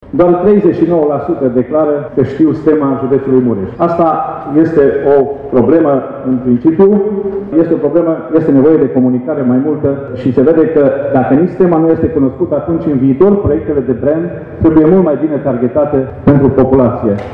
Președintele IRES, Vasile Dâncu.